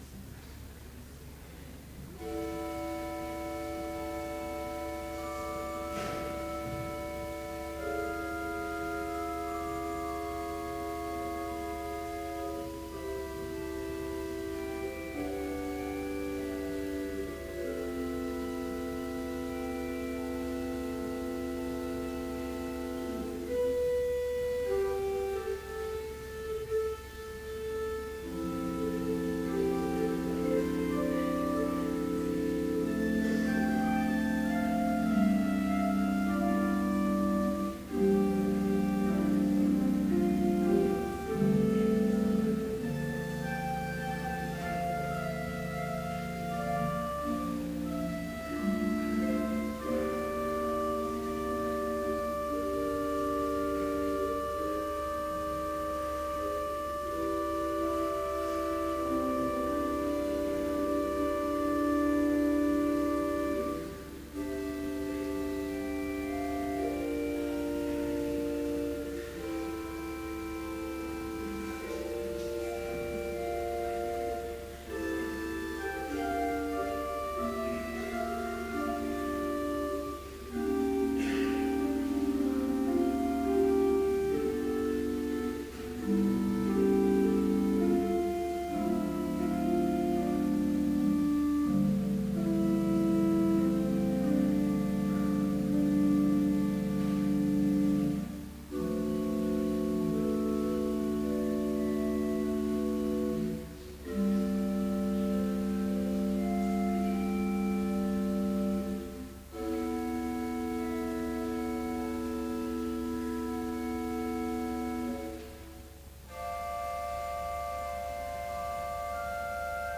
Complete service audio for Easter Week Vespers - April 23, 2014
Listen Complete Service Audio file: Complete Service Sermon Only Audio file: Sermon Only Order of Service Prelude Hymn 343, vv. 1–3, Christ Jesus Lay in Death's Strong Bands Versicles & Gloria Patri Psalm 118:15-24 & Gloria Lesson: I Corinthians 15:17-22 Hymn 343, vv. 4-6, It was a strange… Homily Choir Canticle: Magnificat in b - T. T. Noble